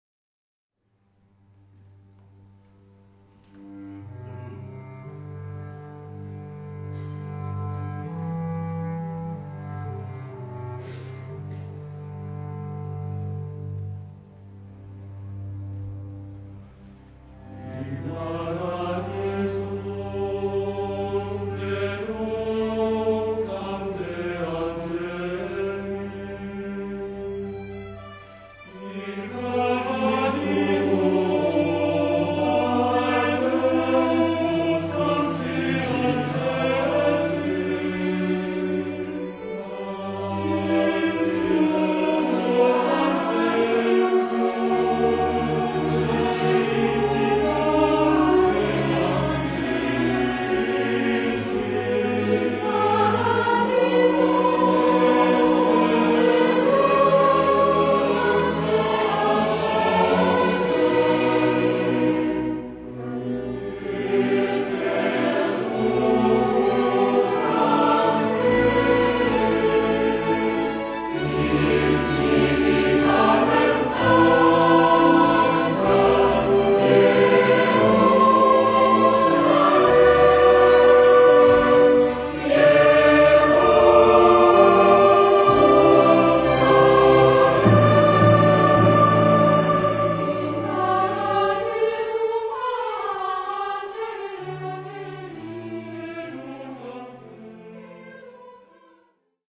SATB (4 voix mixtes) ; Partition complète.
Sacré.